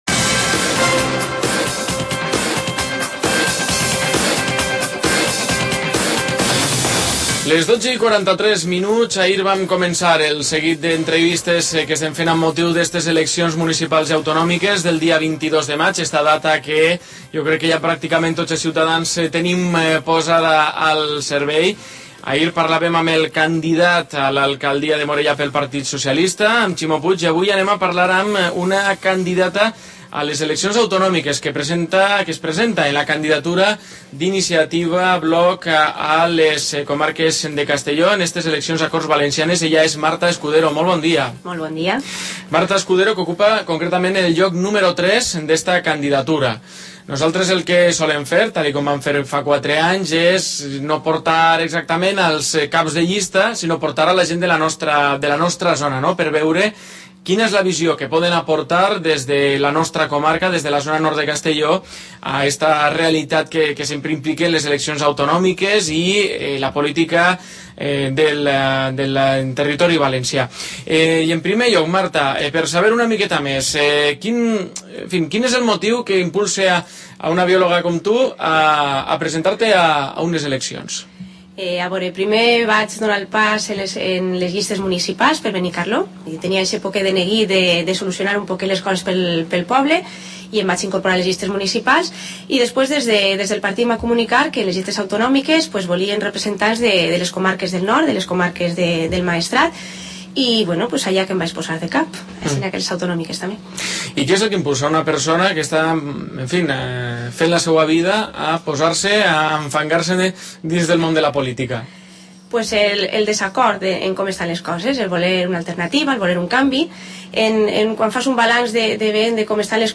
Entrevista
entrevista-COPE.mp3